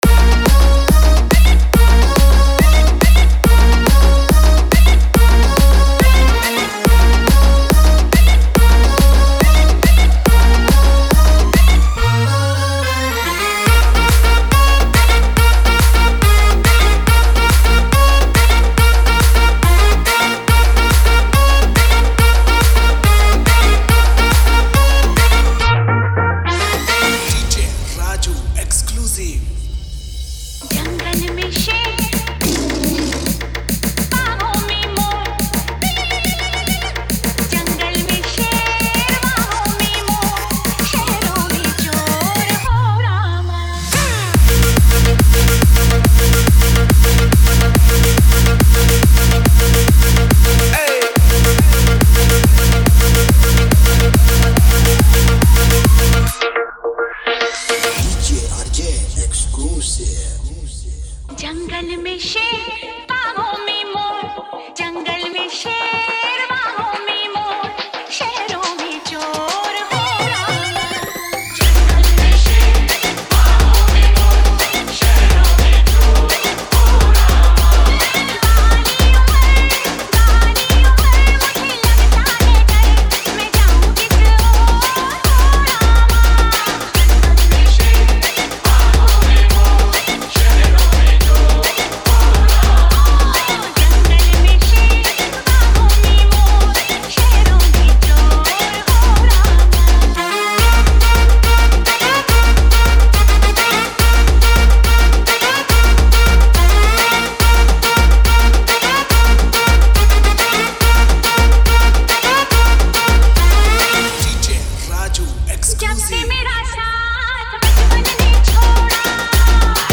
Old Hindi DJ Remix Songs